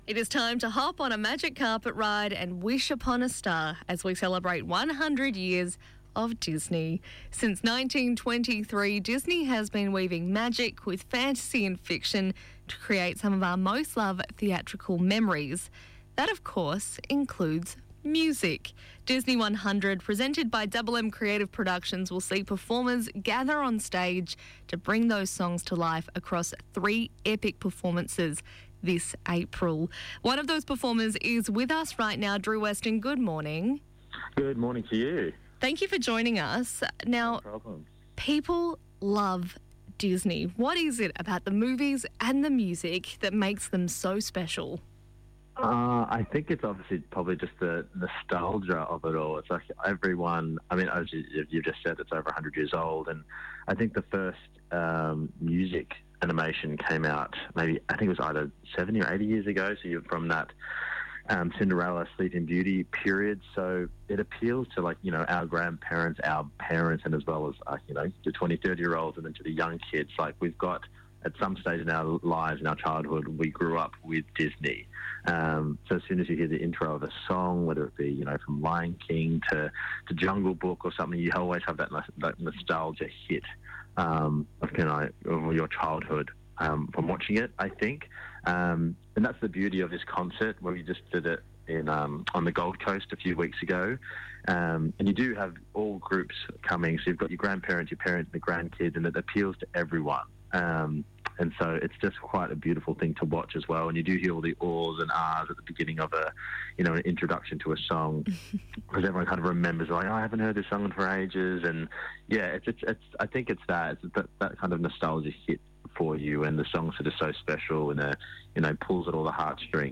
disney-100th-anniversary-interview.mp3